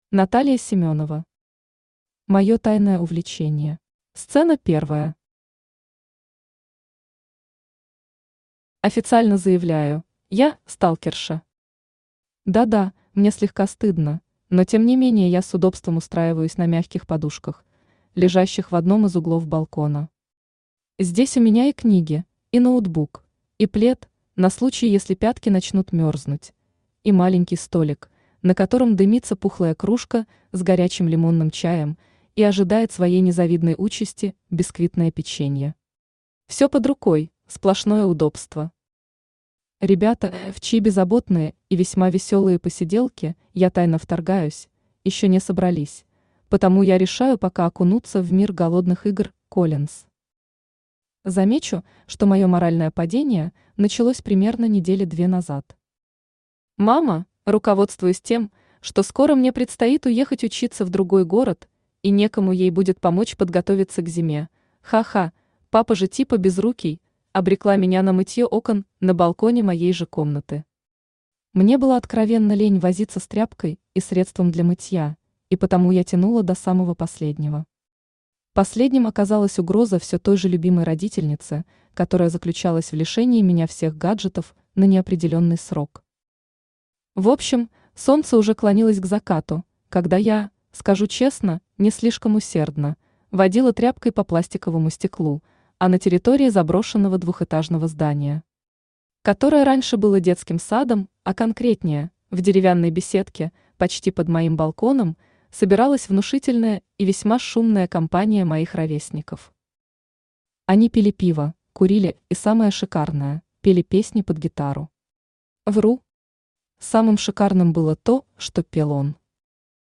Аудиокнига Моё тайное увлечение | Библиотека аудиокниг
Aудиокнига Моё тайное увлечение Автор Наталья Семёнова Читает аудиокнигу Авточтец ЛитРес.